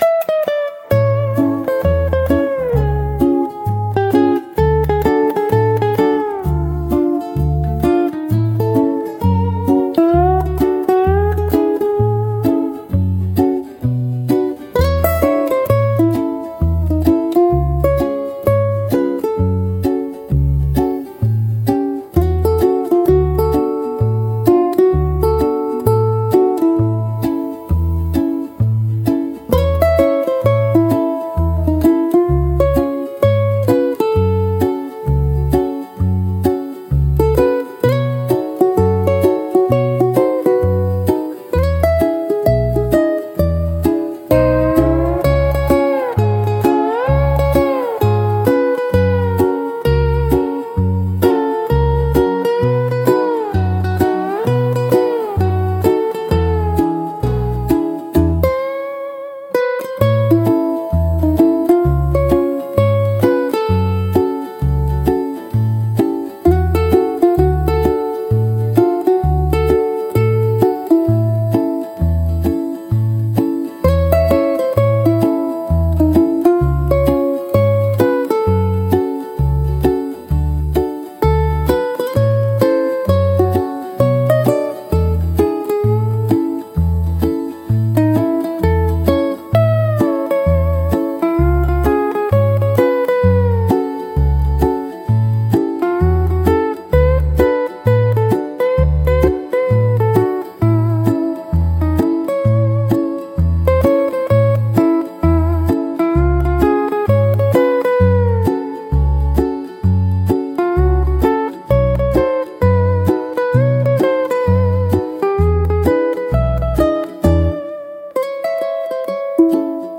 聴く人にゆったりとした安らぎや心地よさを届ける、穏やかで親しみやすいジャンルです。